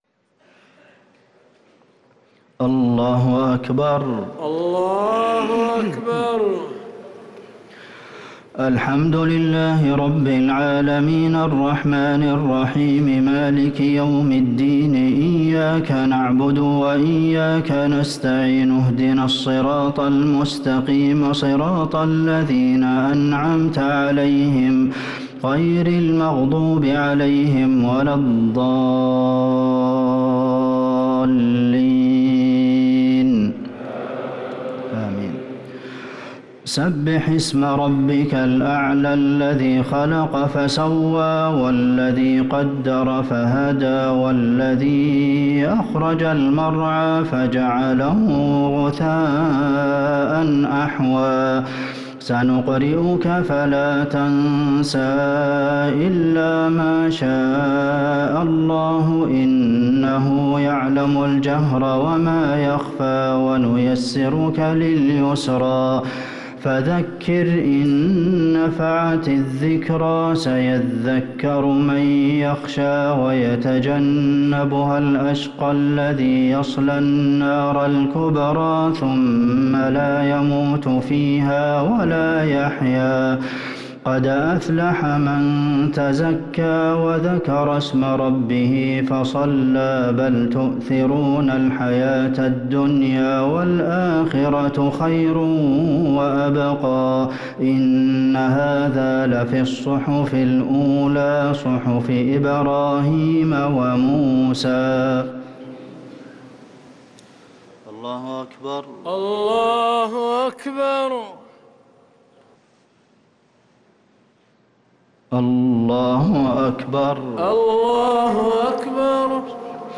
الشفع و الوتر ليلة 15 رمضان 1444هـ | Witr 15 st night Ramadan 1444H > تراويح الحرم النبوي عام 1444 🕌 > التراويح - تلاوات الحرمين